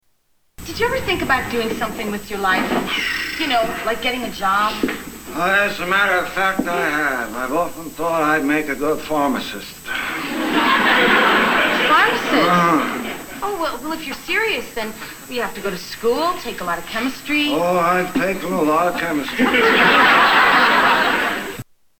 Category: Television   Right: Personal
Tags: Television Taxi TV Series Jim Ignatowsky Christoper Lloyd Comedy